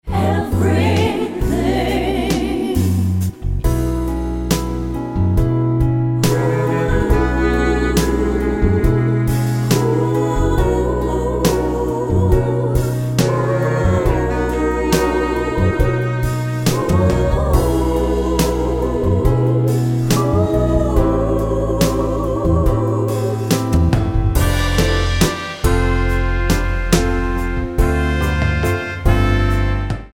--> MP3 Demo abspielen...
Tonart:B-C-C# mit Chor